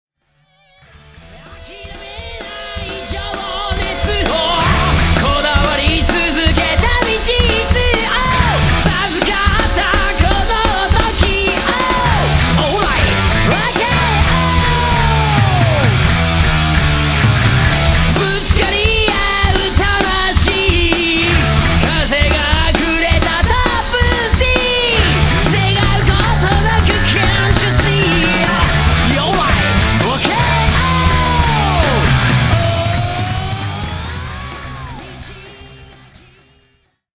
ハード・ロック・サウンドが身上だ。